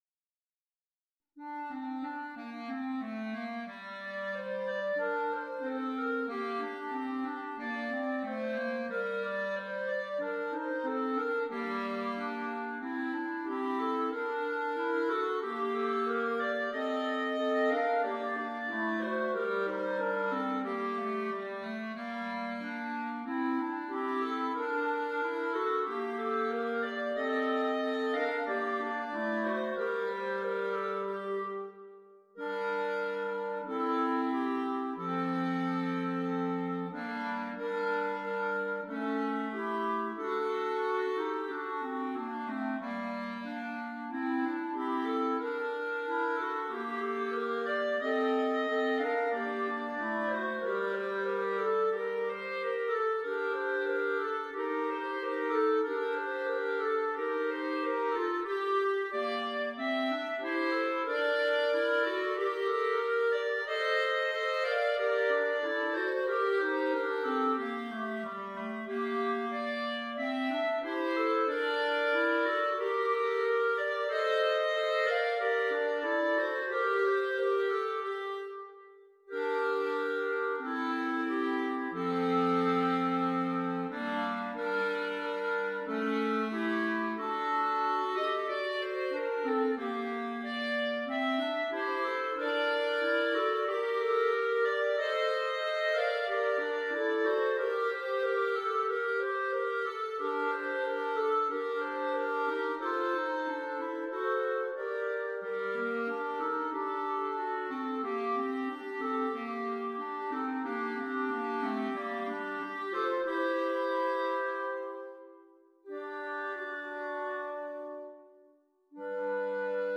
This traditional French tune